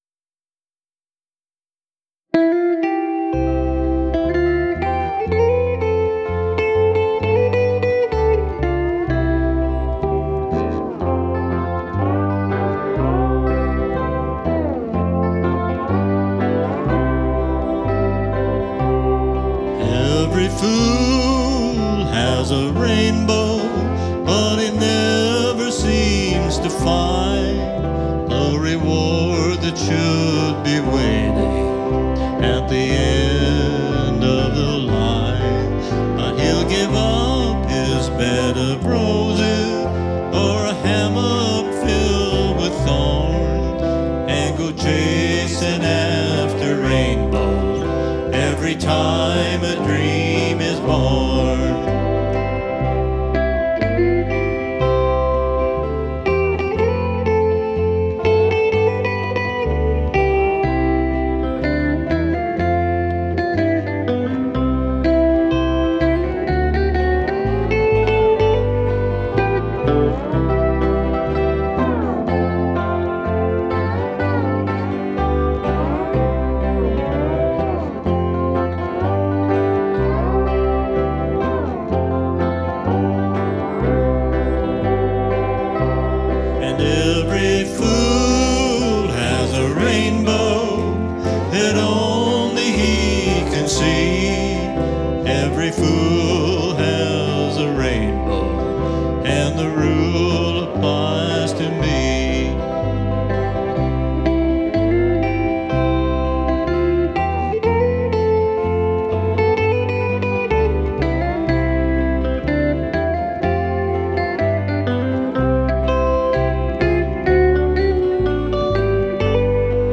Lap Steel